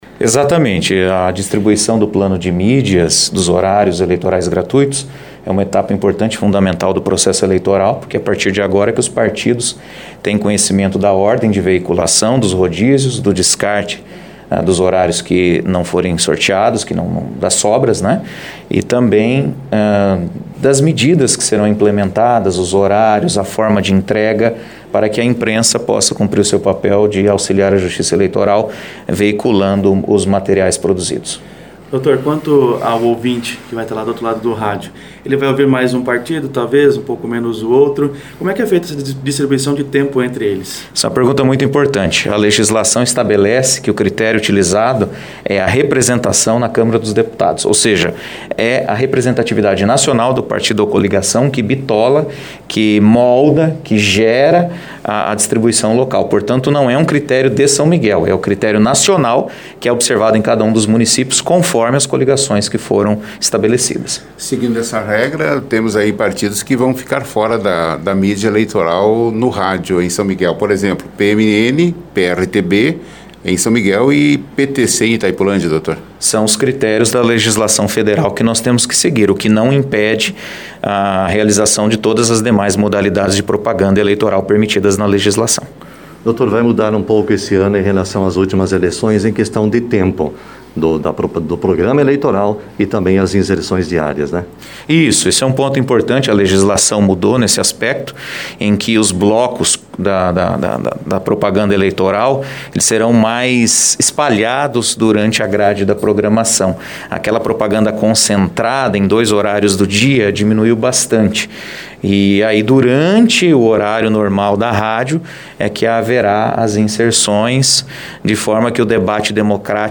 Em entrevista coletiva, o Juiz Eleitoral Ferdinando Scremin Neto explicou o critério para distribuição do tempo entre os partidos. O magistrado também falou sobre a organização para o Pleito Eleitoral em São Miguel do Iguaçu.